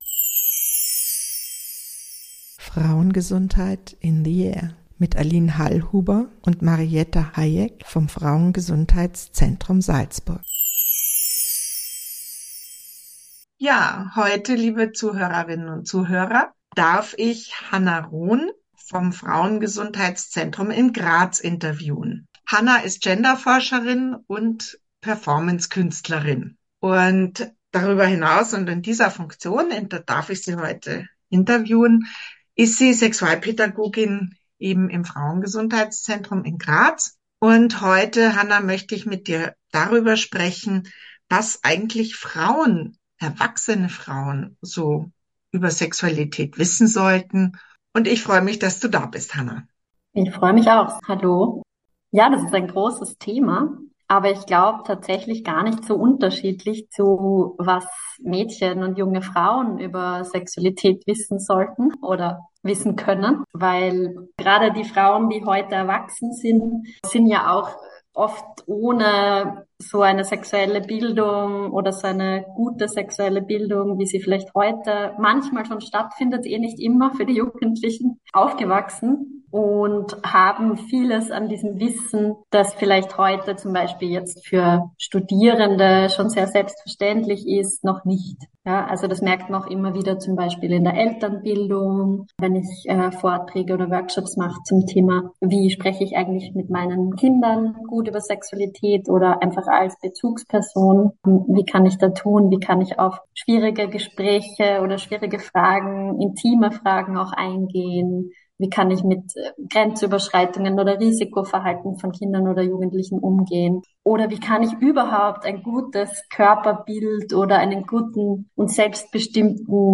Das Wissen darum und über die weibliche Klitoris werden im Interview hinsichtlich einer frauenfreundlichen und feministischen Sexualpädagogik deutlich.